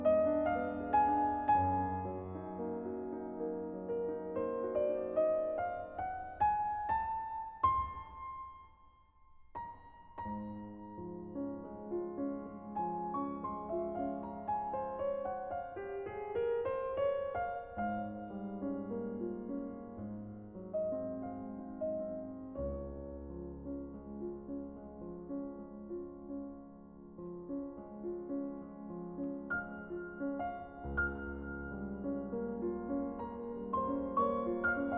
Classical Choral Sacred Piano Romantic
Жанр: Классика